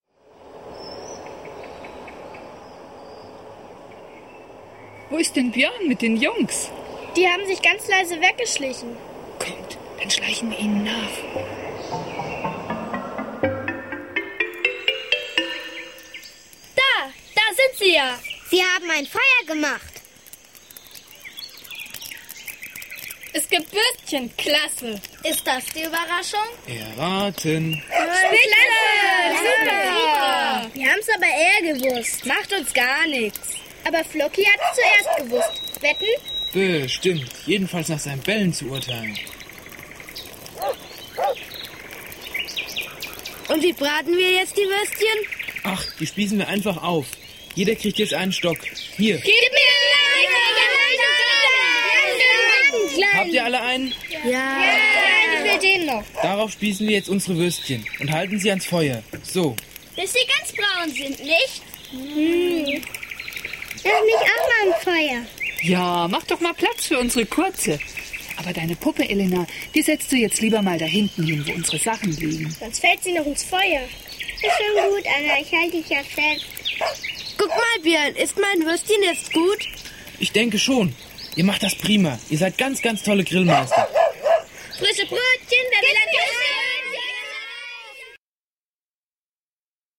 10. Am Lagerfeuer - Hörszene 5